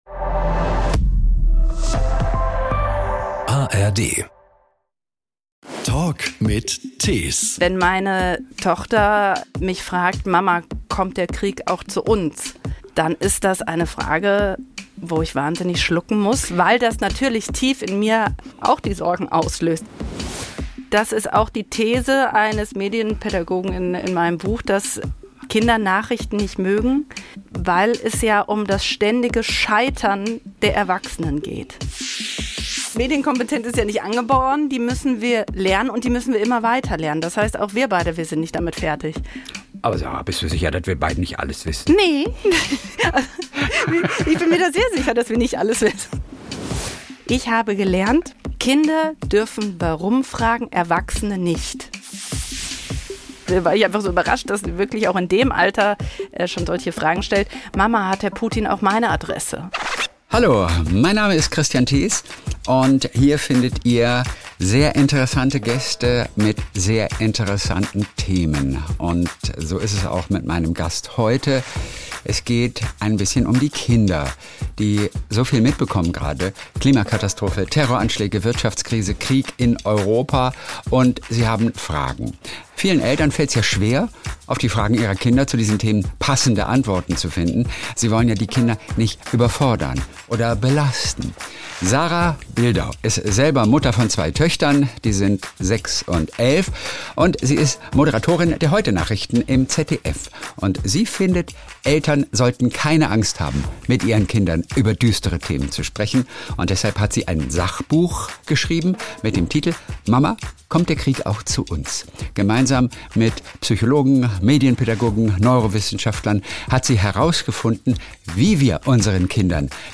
Jeden Sonntag unterhält er sich mit Stars, Promis und interessanten Menschen verschiedener Branchen. Kristian hat einfach Lust auf seine Gesprächspartner und spricht über die besonderen Geschichten der Popstars, Schauspieler, Autoren & Co..